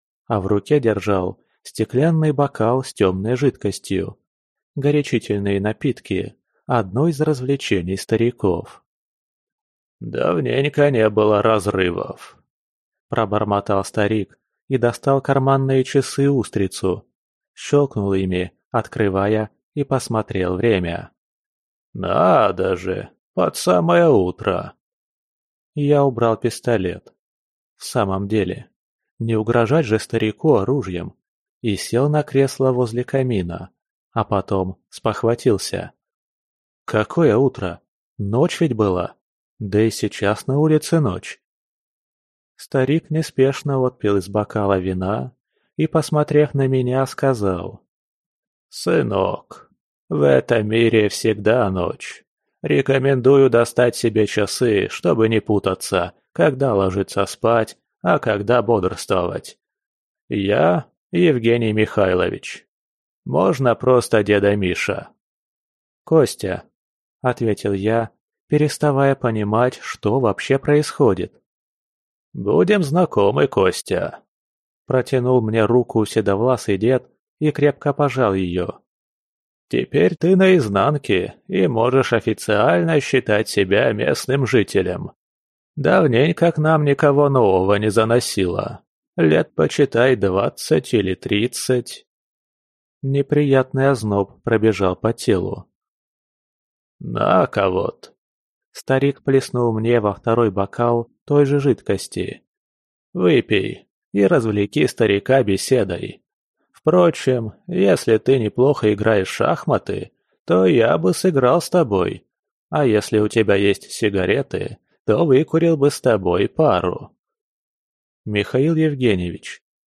Аудиокнига Двойник. Книга 2. На Изнанке | Библиотека аудиокниг
Прослушать и бесплатно скачать фрагмент аудиокниги